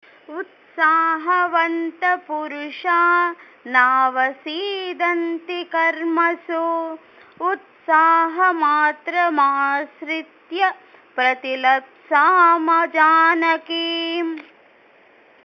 Audio Recitation